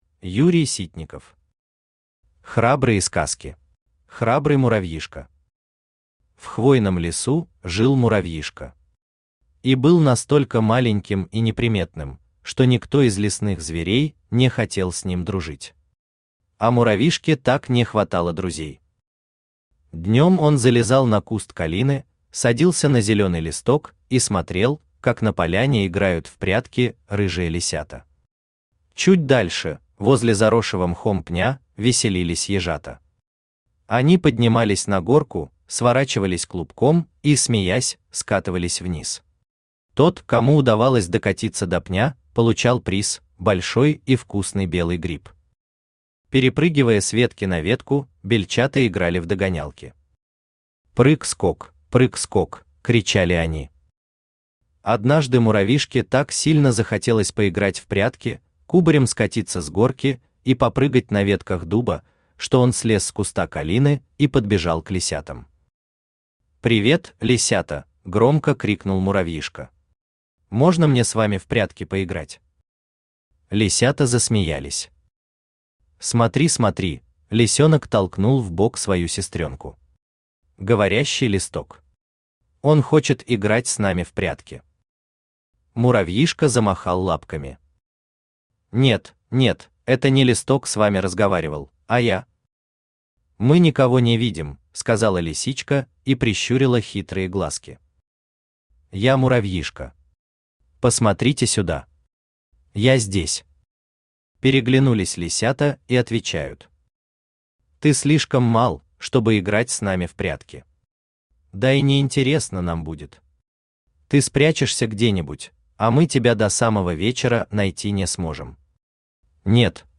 Аудиокнига Храбрые сказки | Библиотека аудиокниг
Aудиокнига Храбрые сказки Автор Юрий Вячеславович Ситников Читает аудиокнигу Авточтец ЛитРес.